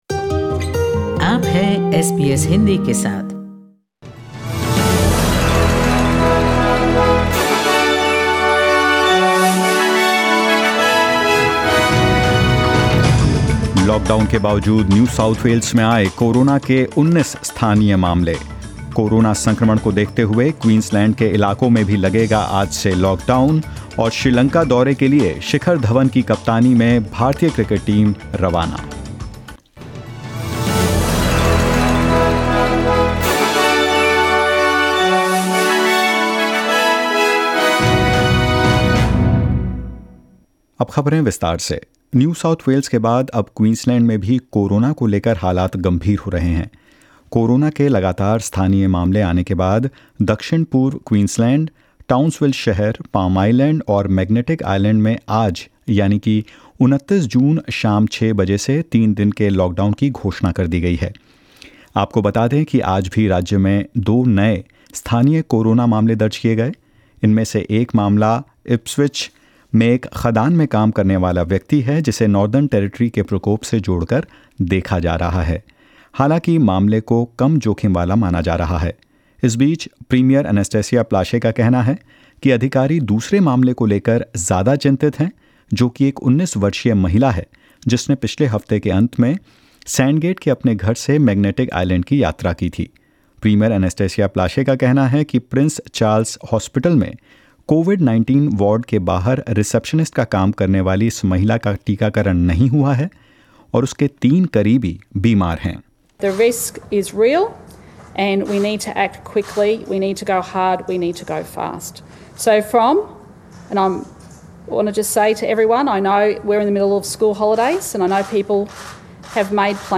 In this latest SBS Hindi News bulletin of Australia and India: The Northern Territory records two more coronavirus cases; Victoria ticked off a third consecutive day without any locally acquired coronavirus cases and more.